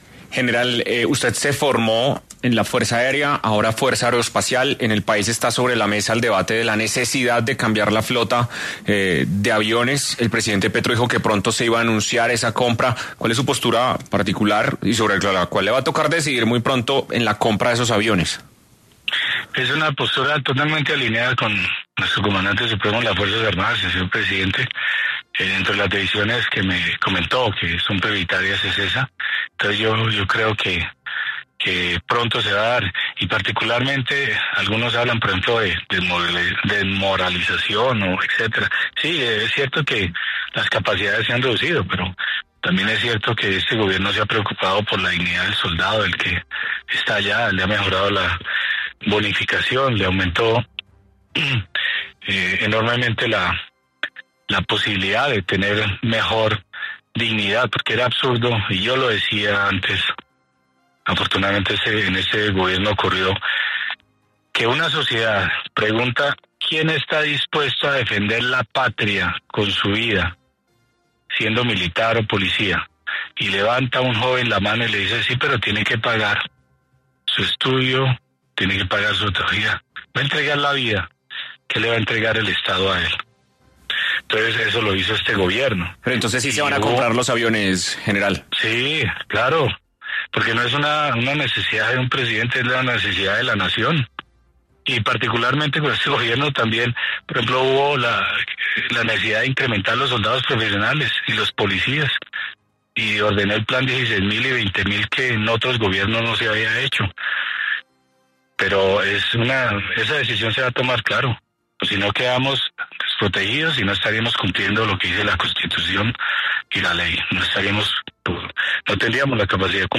En conversación con 6AM de Caracol Radio, el general que presentó su petición de retiro, Pedro Sánchez, quien será el nuevo ministro de defensa, habló sobre diferentes aspectos que tiene pensados para la administración de esta cartera.